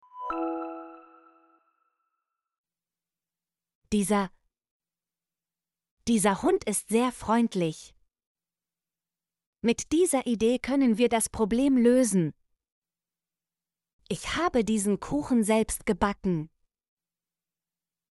dieser - Example Sentences & Pronunciation, German Frequency List